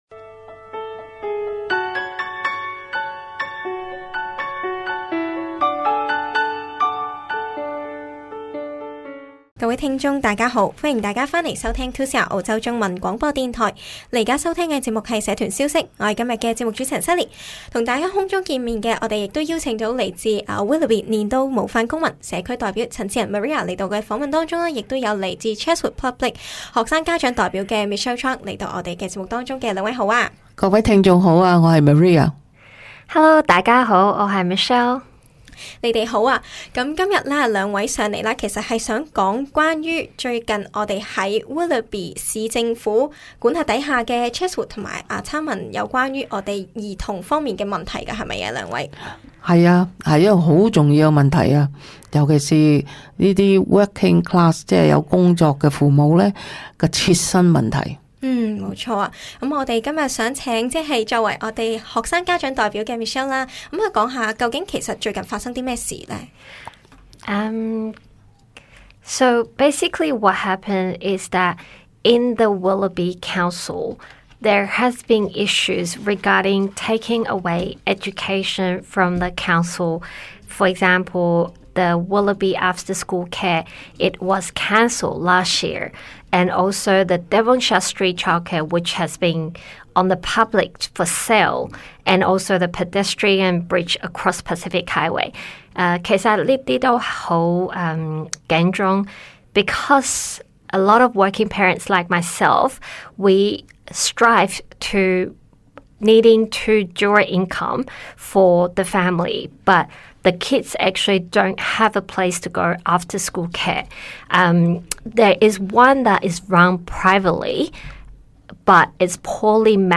嘉宾专访